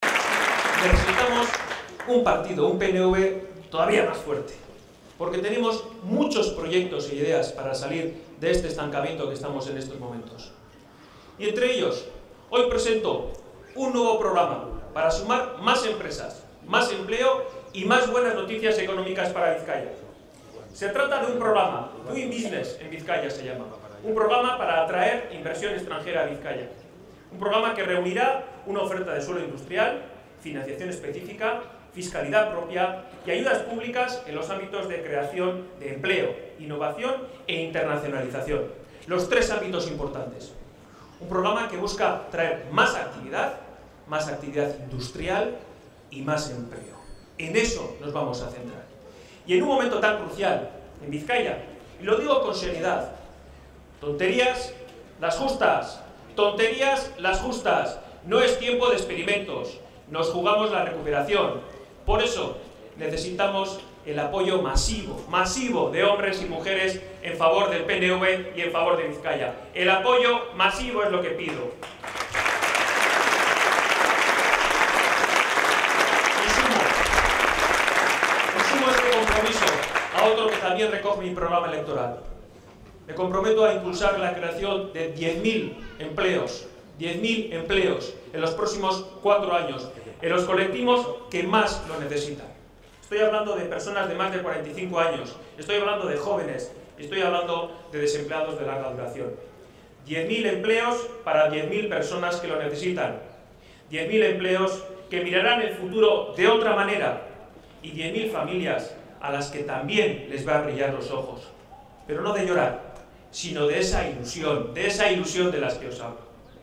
El anuncio de su nuevo compromiso lo ha hecho durante la presentación de las candidaturas que EAJ-PNV presentará a las alcaldías de Padura, acto que se ha celebrado en Arrigorriaga esta mañana